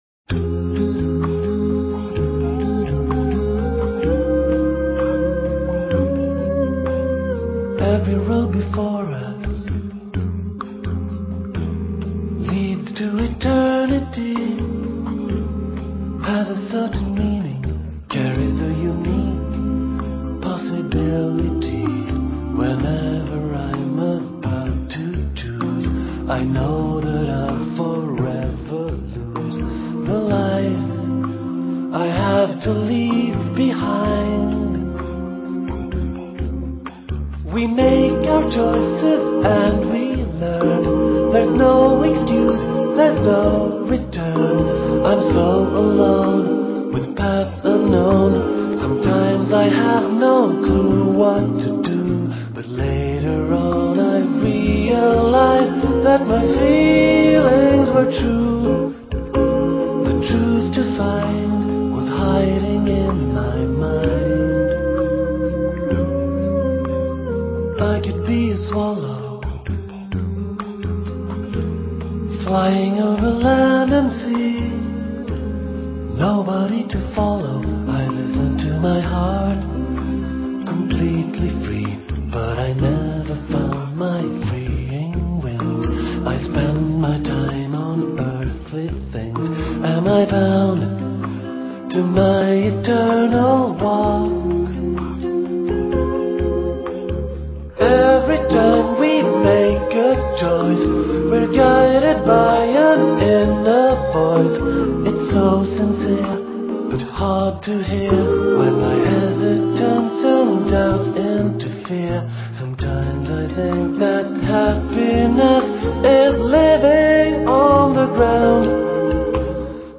Vocal jazz